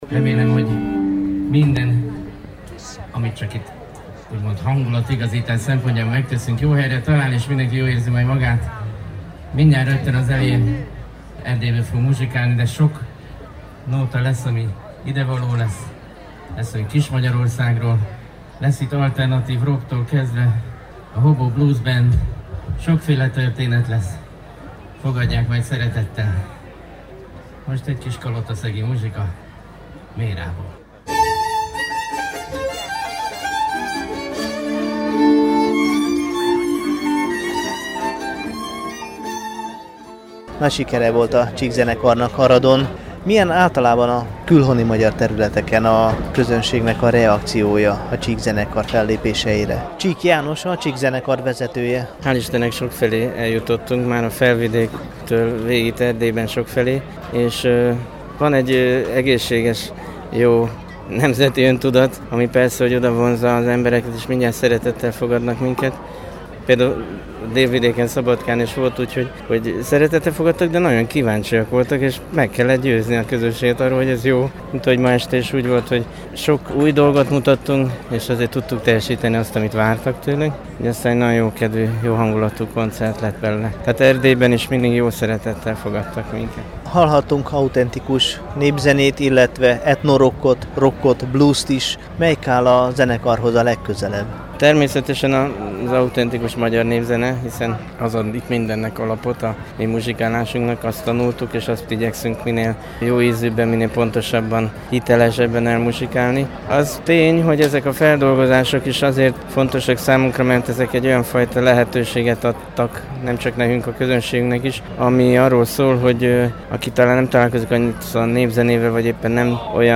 „Legnagyobb kincsünk a népzenénk” – interjú Csík Jánossal, a Csík Zenekar vezetőjével [AUDIÓ]
A múlt szombati 13. Aradi Magyar Majálist a magyarországi Csík Zenekar koncertje zárta.
A több mint egyórás program végén a zenekarvezető, Csík János készséggel állt mikrofonja elé.
Csik_Zenekar_Aradon.mp3